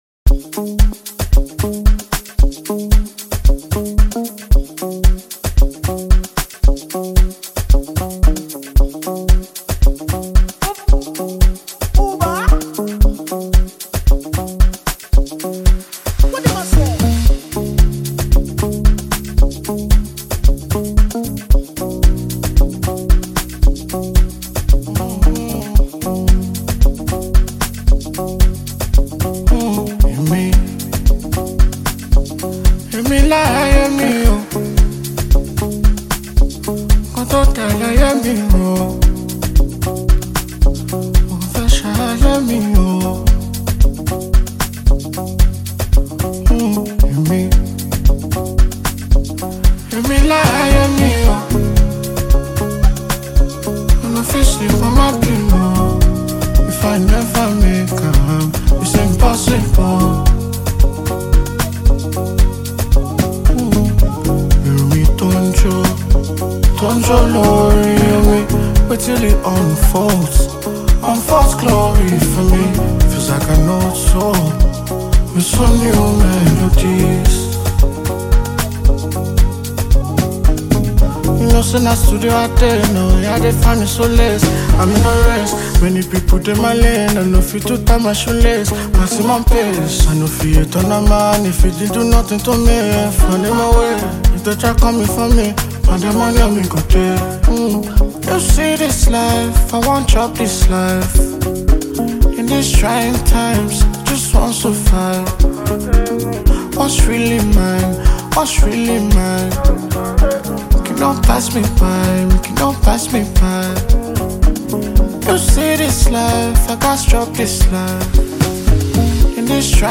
Nigeria Afrobeats sensational singer-songwriter